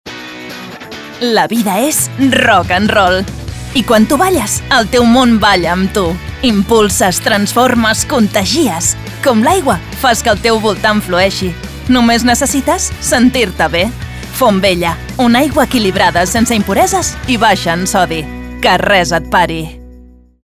Warm, Zacht, Natuurlijk, Vriendelijk, Jong
Commercieel